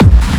Kick 2.wav